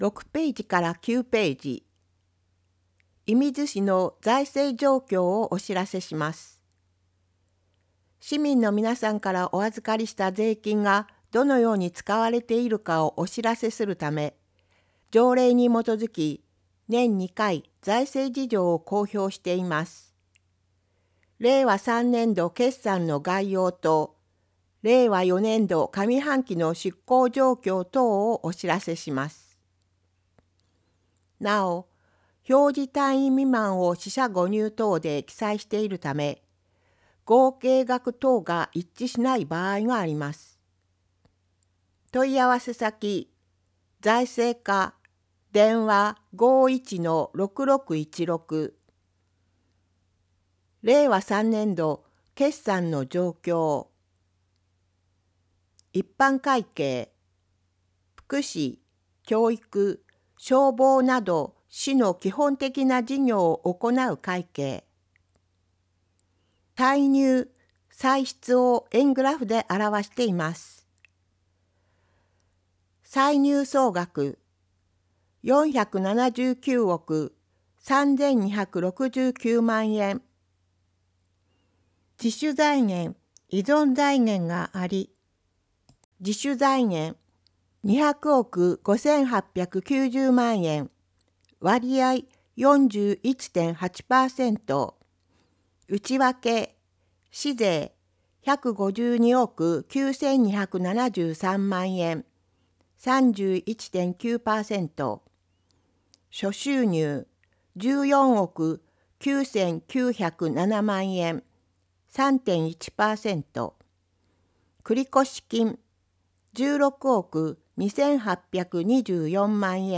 広報いみず 音訳版（令和４年１２月号）｜射水市